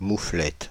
Ääntäminen
Ääntäminen France (Île-de-France): IPA: /mu.flɛt/ Haettu sana löytyi näillä lähdekielillä: ranska Käännöksiä ei löytynyt valitulle kohdekielelle.